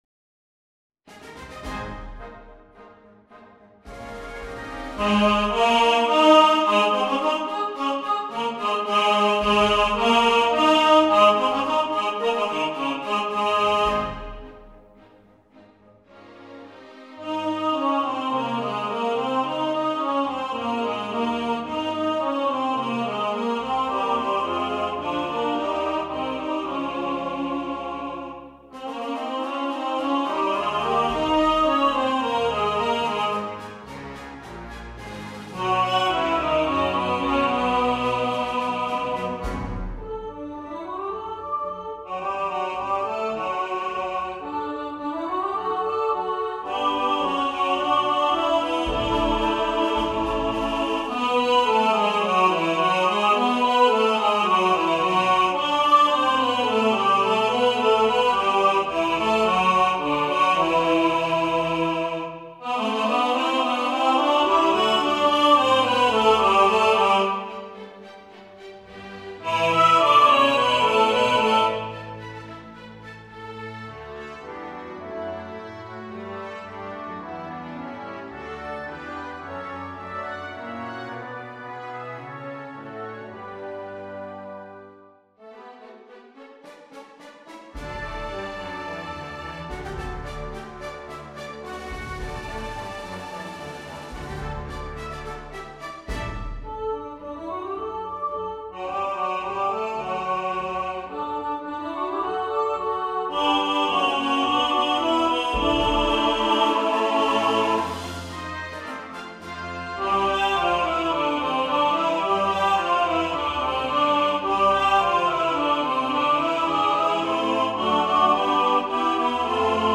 Spirit Of The Season All Voices | Ipswich Hospital Community Choir